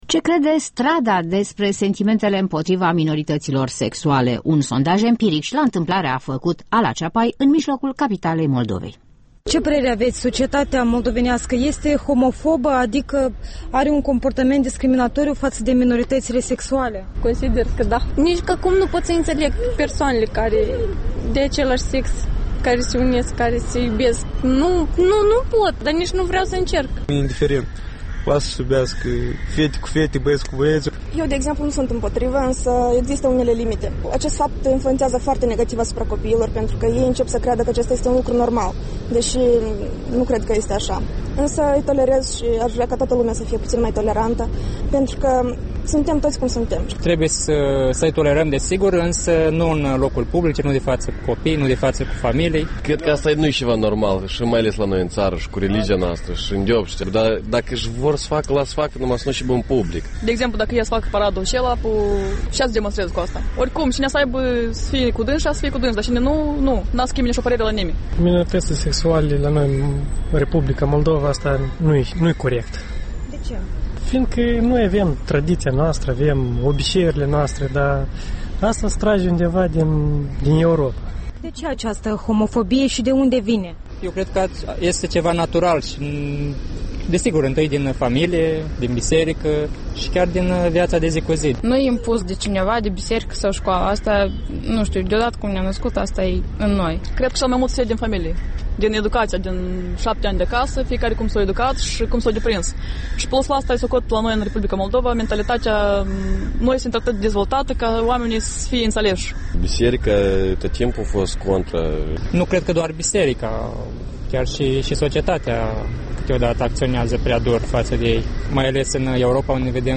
Cu microfonul Europei Libere pe străzile Chișinăului